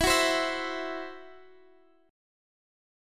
Esus2#5 Chord (page 2)
Listen to Esus2#5 strummed